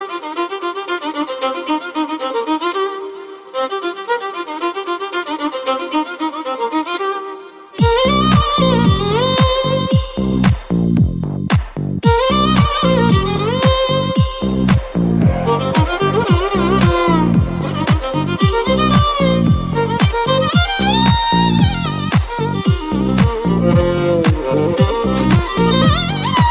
Kategorien Soundeffekte